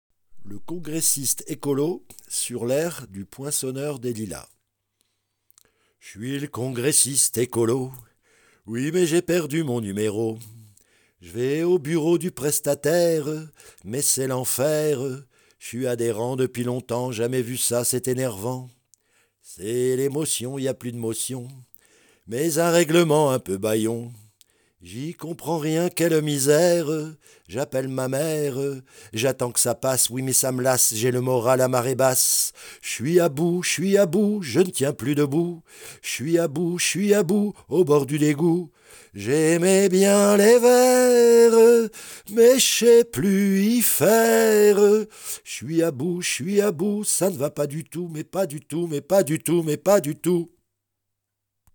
Le-Congressiste-Ecolo-A-CAPELLA.mp3